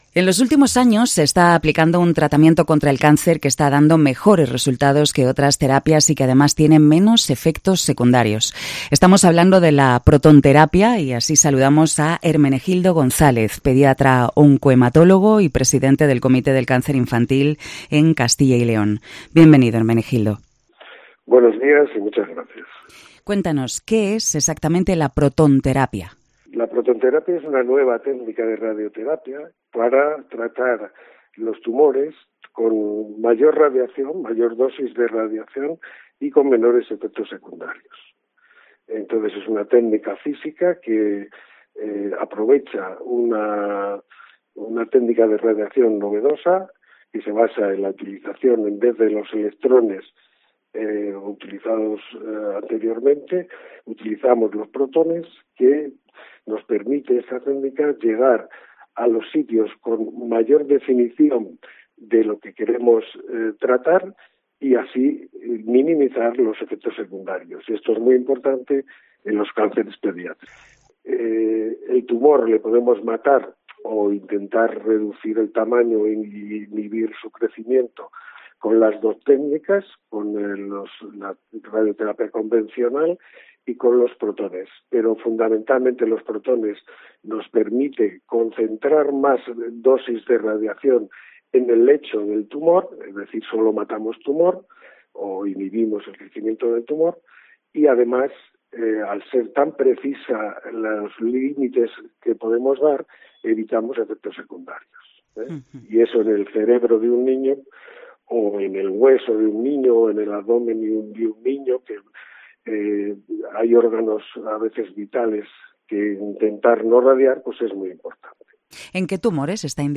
COPE Salamanca entrevista al doctor